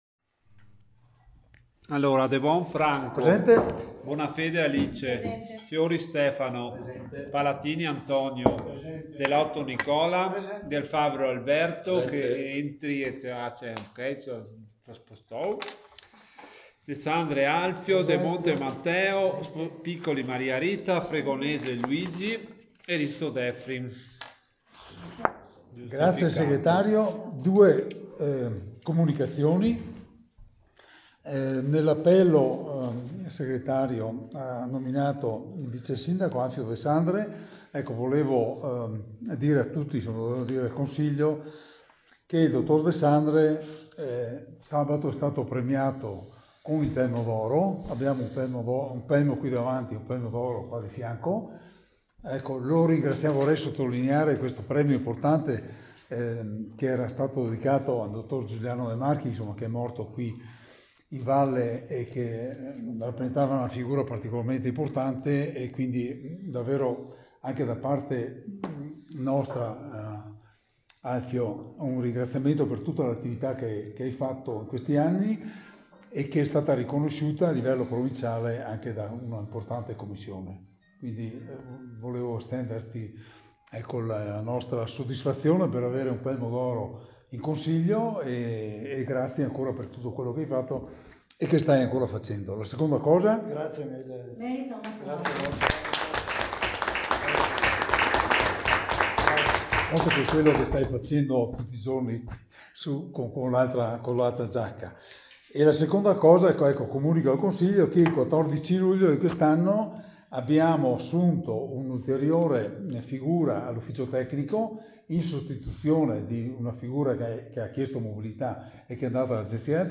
Registrazione audio Consiglio Comunale